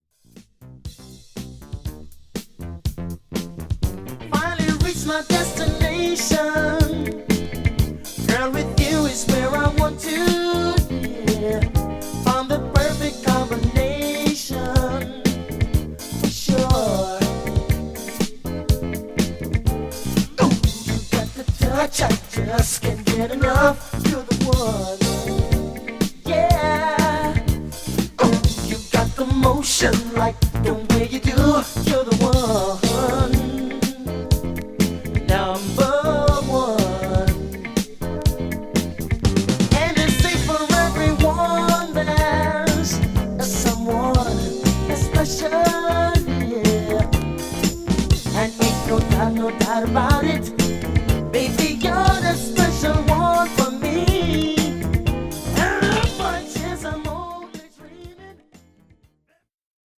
ホーム ｜ SOUL / FUNK / RARE GROOVE / DISCO > SOUL
A1,2、B1,4といったモダン・ファンク〜ダンサー・チューンが充実したアルバムです。